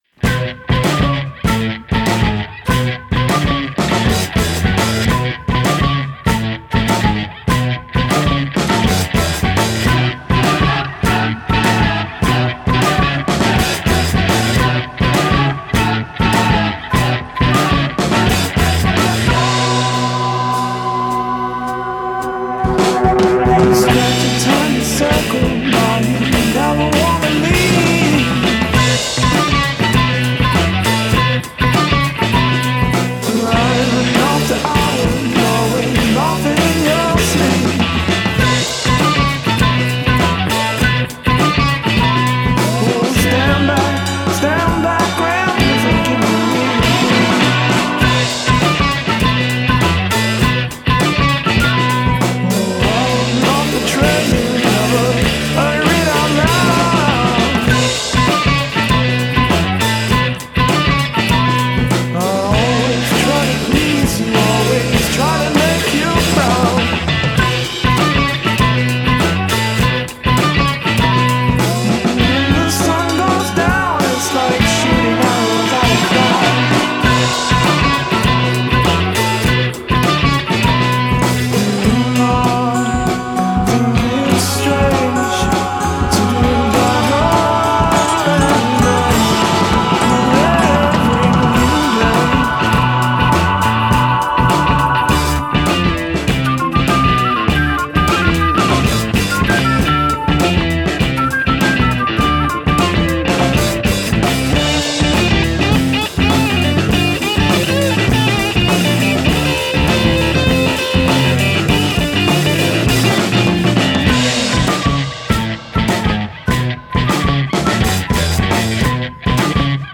Be prepared for some rock
vocals/guitar
bass